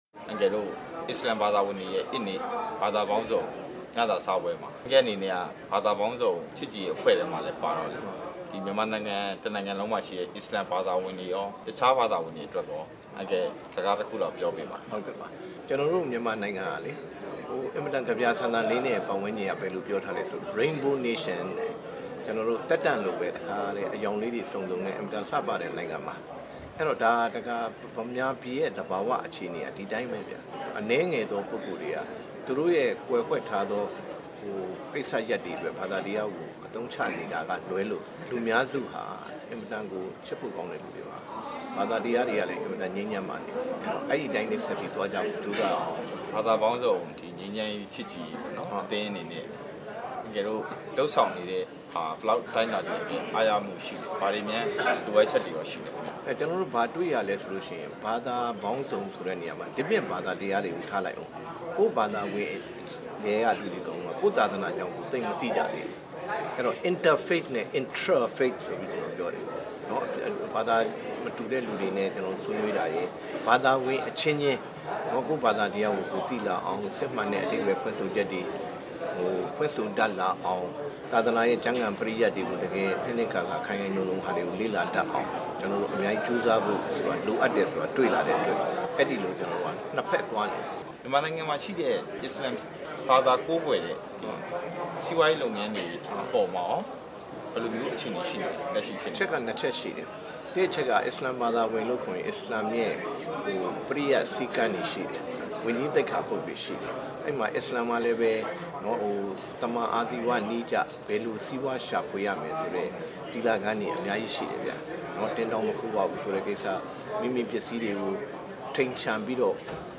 မေးမြန်း ချက်